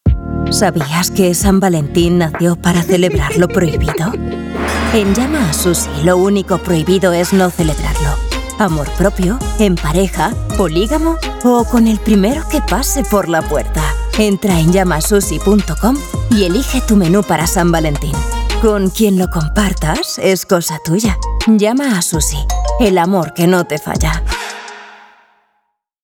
Mis demos
Poseo un rango vocal amplio que me permite transitar con fluidez entre tonos juveniles, dinámicos, corporativos, cálidos y narrativos
Mi estudio es mi centro de operaciones: acústicamente tratado y equipado con hardware profesional para asegurar una calidad impecable, lista para cualquier estándar de emisión.
CUNA_SENSUAL_LLAMASUSHI.mp3